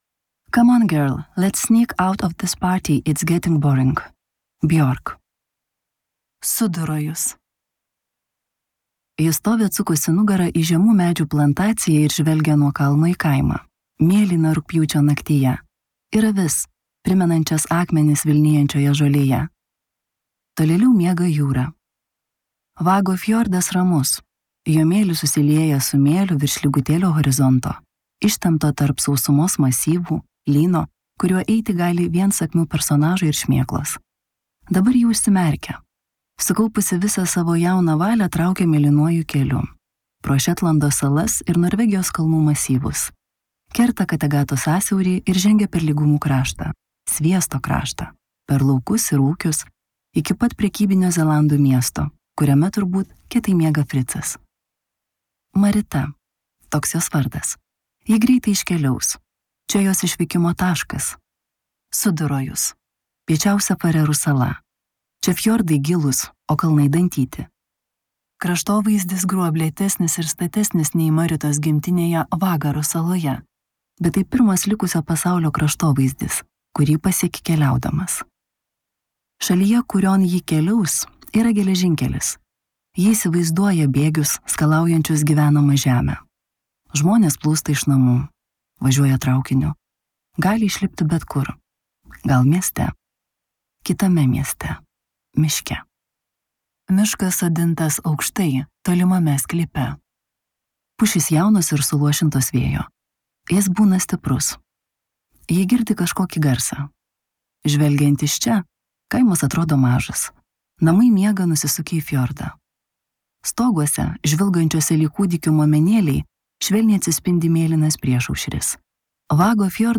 Danų rašytojos Siri Ranva Hjelm Jacobsen audioknyga „Sala“ – debiutinis autorės romanas, apdovanotas tarptautine MarEtica premija.